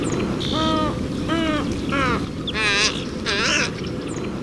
Tricolored Heron
VOZ: Un graznido al ser molestada. No es muy sonora fuera de las colonias de cría.